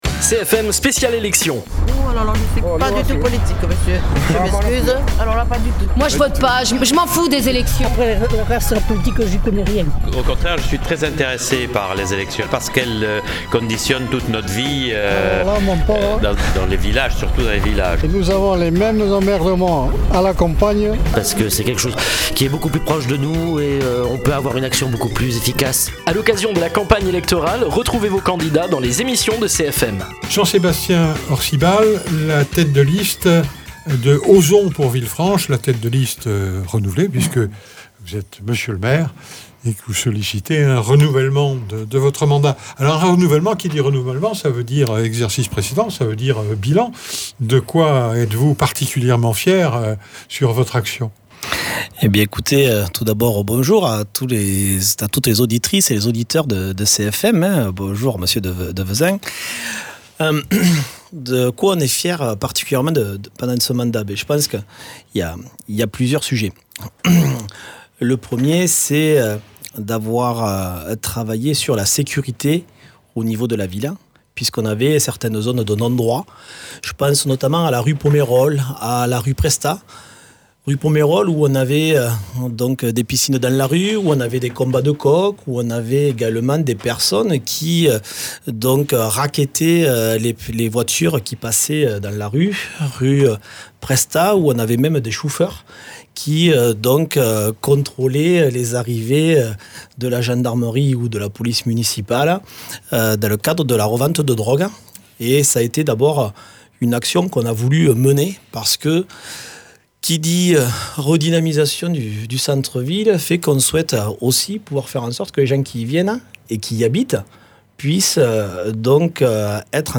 Jean-Sébastien Orcibal, maire sortant et candidat à la mairie de Villefranche-de-Rouergue, présente son projet pour la commune.
Invité(s) : Jean-Sébastien Orcibal, pour la liste Osons pour Villefranche.